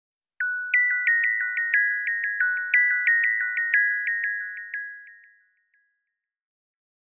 Catégorie: Alarmes